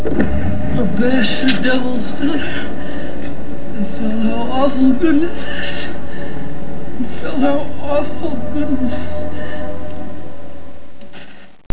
Awful -T_Bird talking to Eric before being blown up.